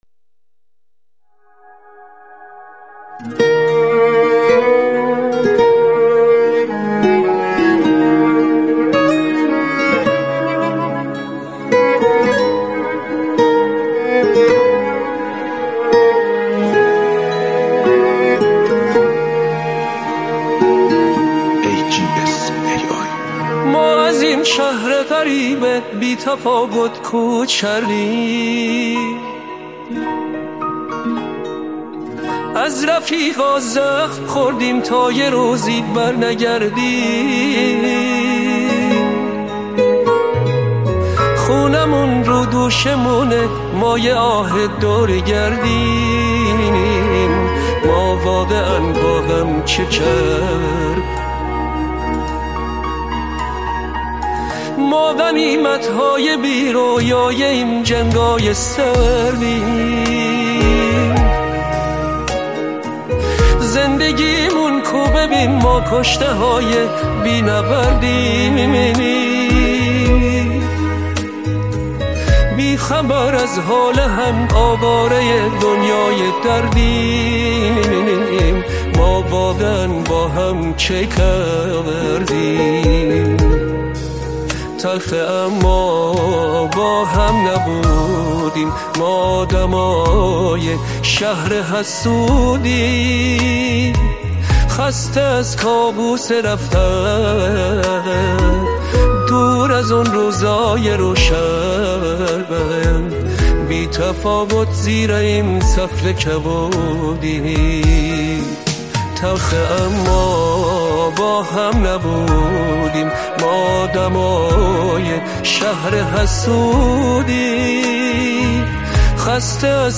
ژانر: پاپ